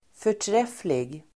Uttal: [för_tr'ef:lig]